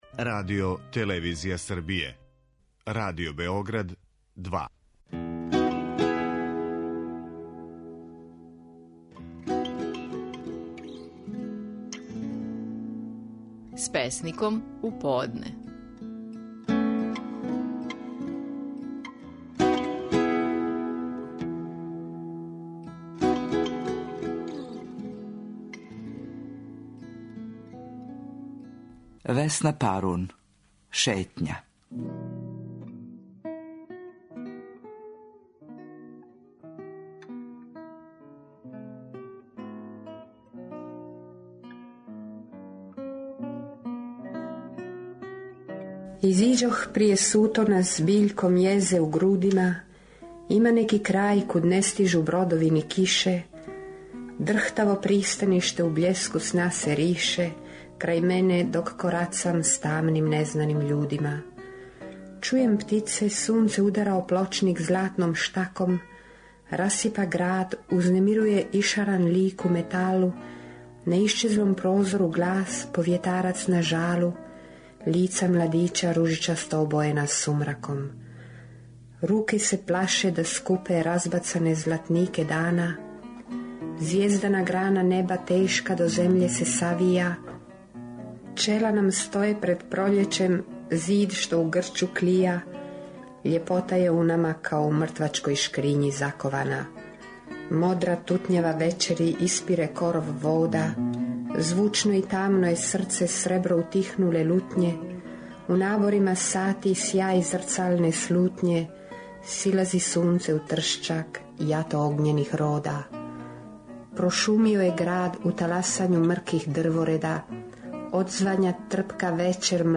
Стихови наших најпознатијих песника, у интерпретацији аутора.
Весна Парун говори своју песму „Шетња".